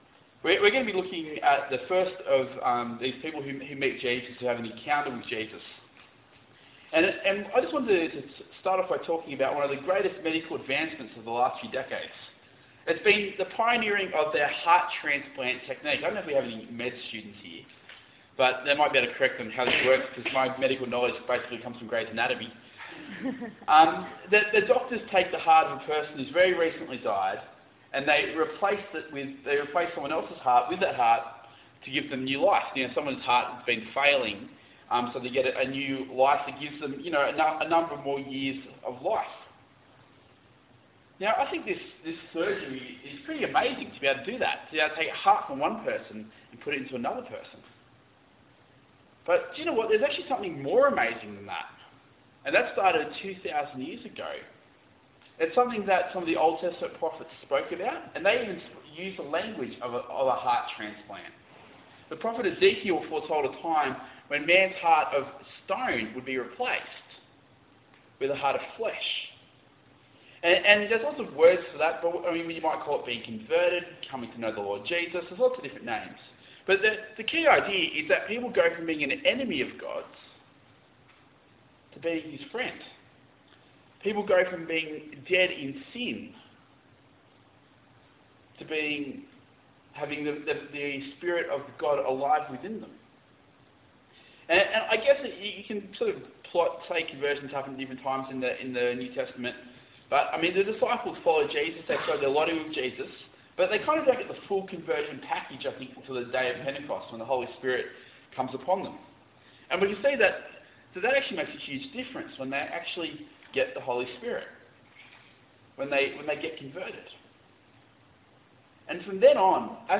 Acts 9:1-30 Talk Type: Bible Talk Paul meets Jesus for the first time…